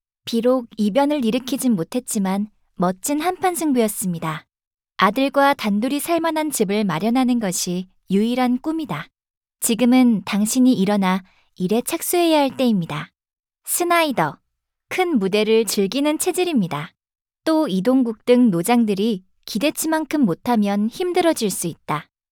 数据堂TTS录音棚通过清华大学建筑环境检测中心检测，达到专业级NR15声学标准，混响时间小于0.1秒，背景噪音小于20dB(A)。
韩语，甜美女声